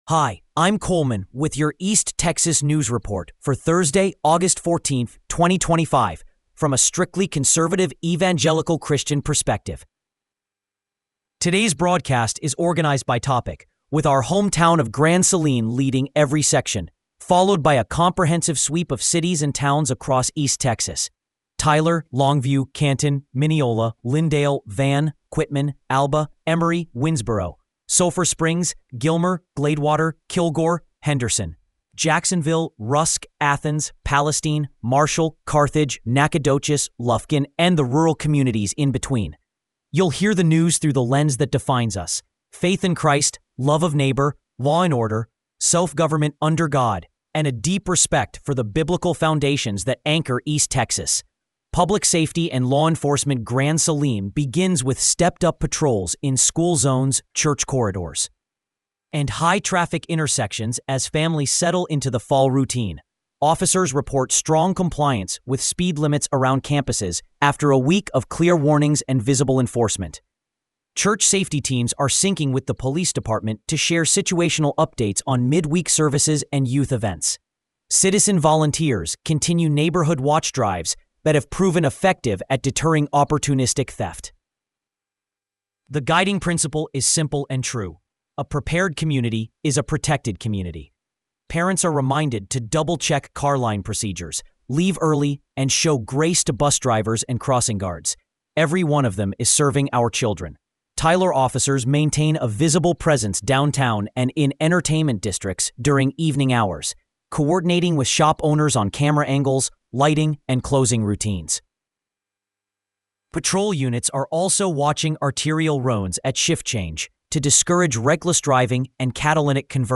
Today’s broadcast is organized by topic.